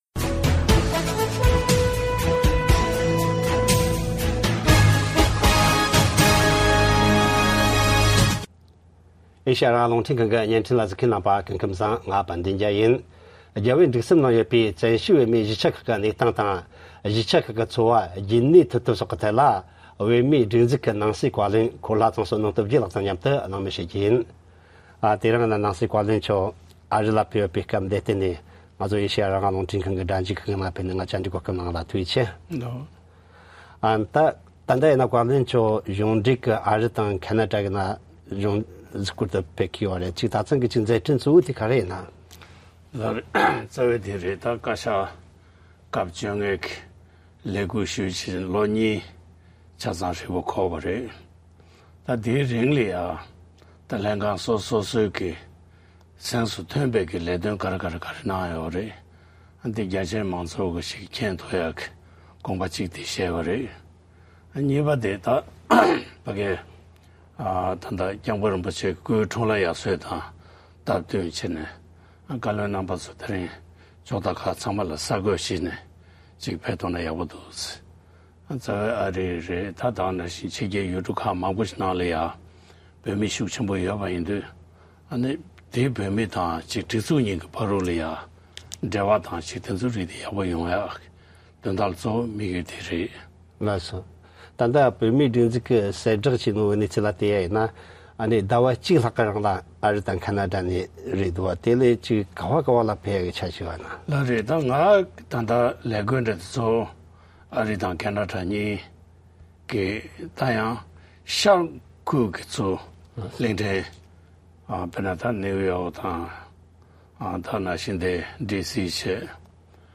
བོད་མིའི་སྒྲིག་འཛུགས་ཀྱི་ནང་སྲིད་བཀའ་བློན་འཁོར་ལྷ་ཚང་བསོད་ནམས་སྟོབས་རྒྱལ་ལགས་ཀྱི་ལྷན་གླེང་བ།